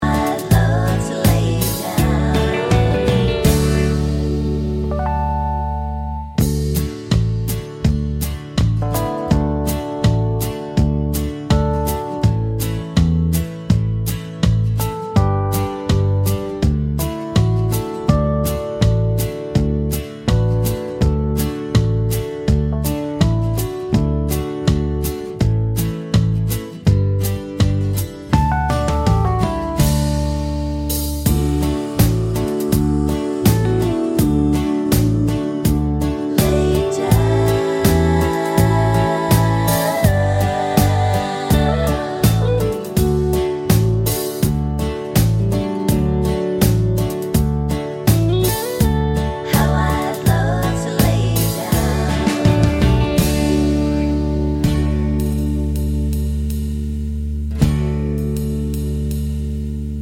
no Backing Vocals Country (Male) 3:23 Buy £1.50